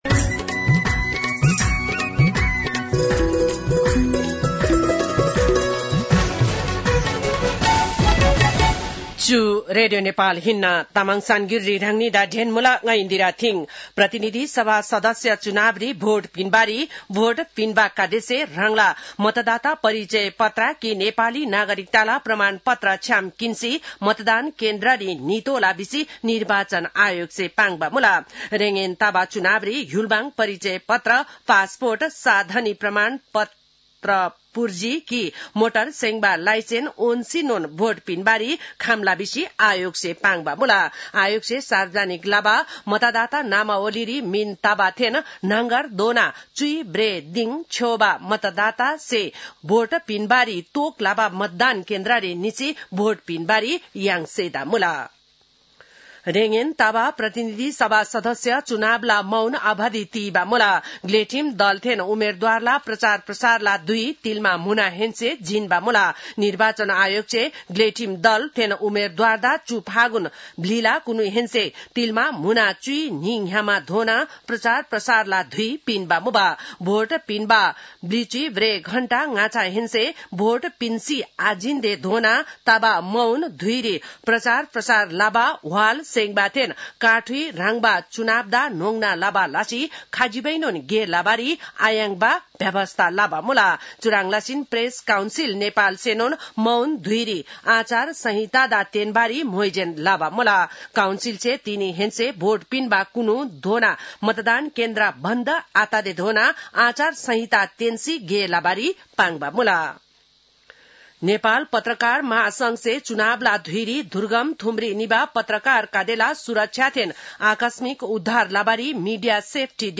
तामाङ भाषाको समाचार : १९ फागुन , २०८२